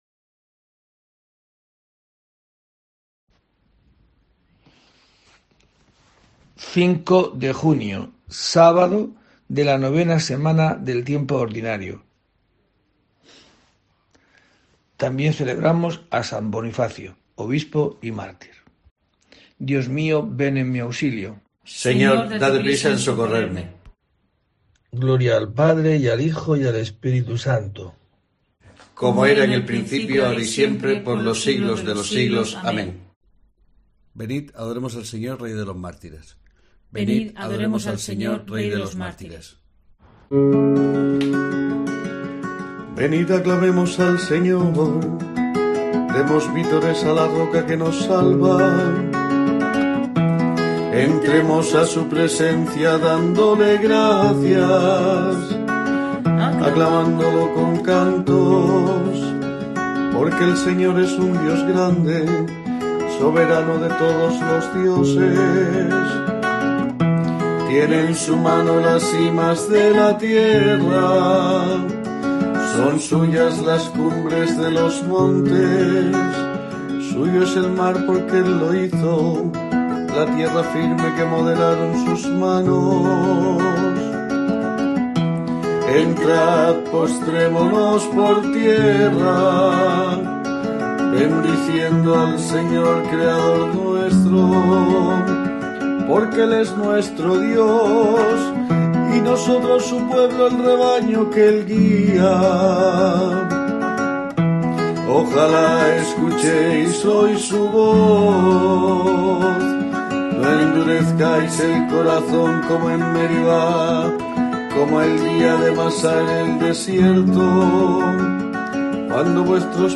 05 de junio: COPE te trae el rezo diario de los Laudes para acompañarte